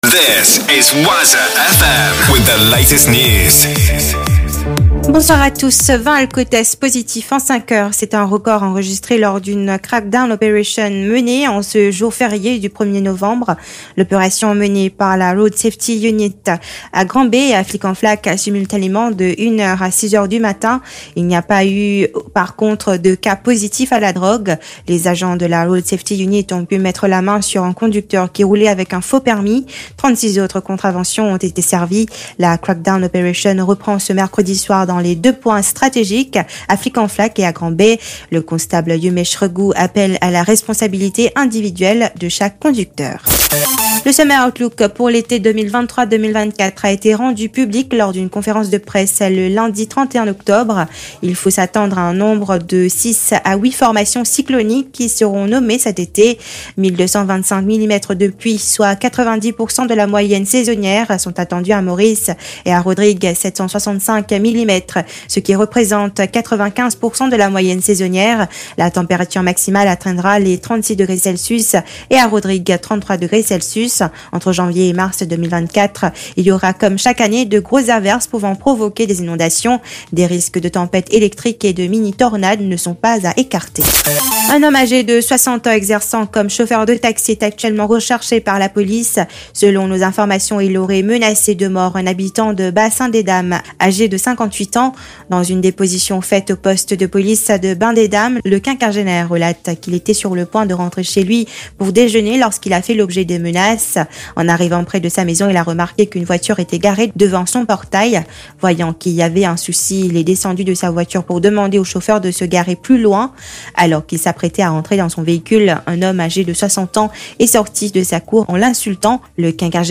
NEWS 17 H - 1.11.23